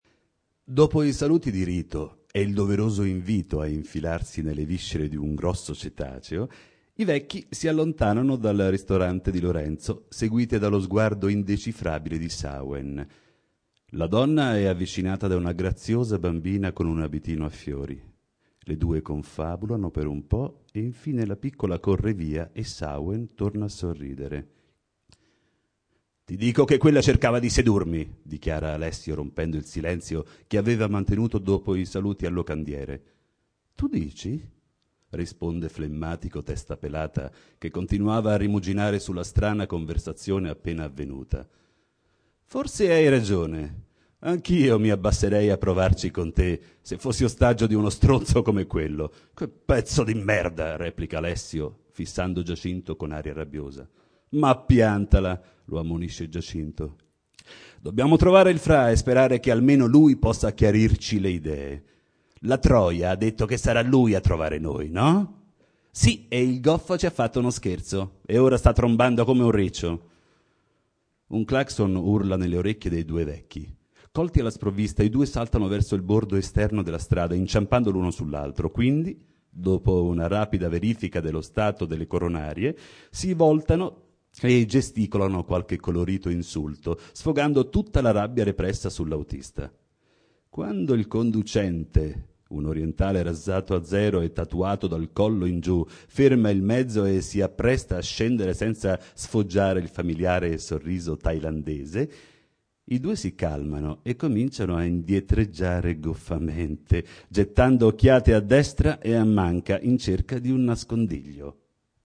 Letture di brani scelti